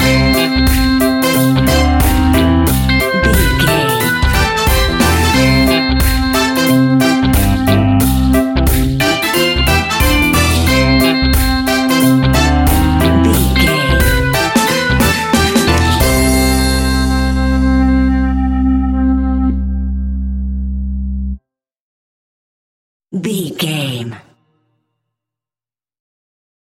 Take me back to the old skool retro seventies reggae sounds!
Ionian/Major
reggae instrumentals
laid back
chilled
off beat
drums
skank guitar
hammond organ
percussion
horns